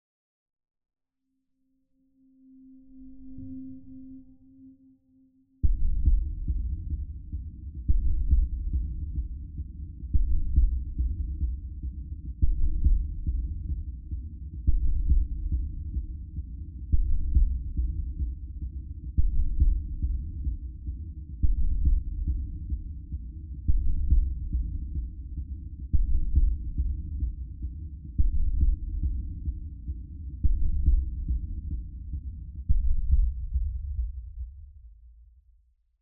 STest1_Left200Hz.wav